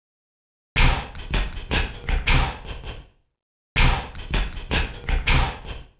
UZ_percussion_loop_beatbox_160.wav